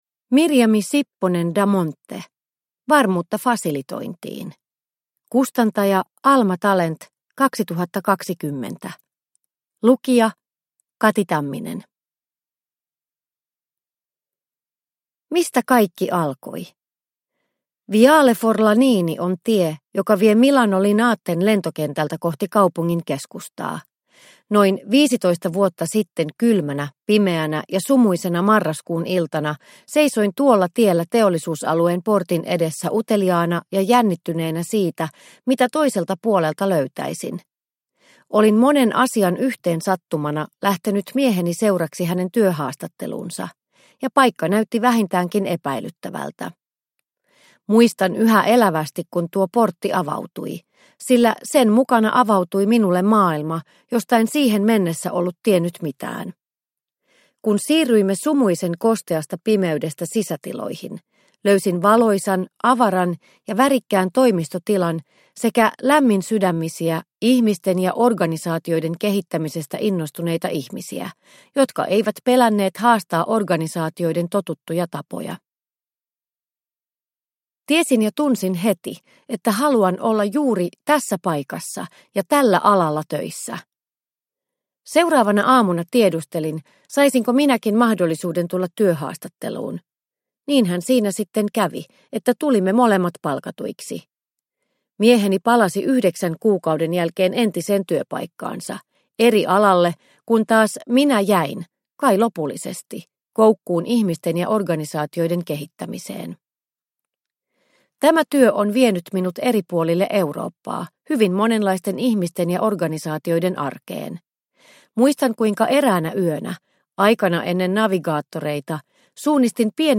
Varmuutta Fasilitointiin – Ljudbok – Laddas ner